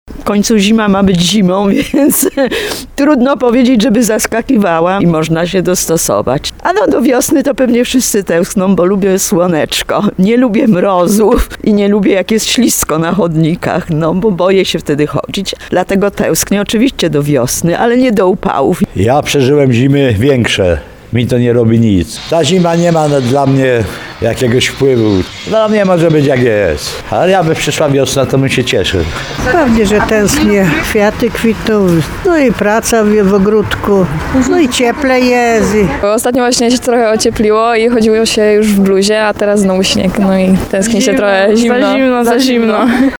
Zapytaliśmy mieszkańców Tarnowa co sądzą o tegorocznej prawdziwej zimie i czy tęsknią za wiosną.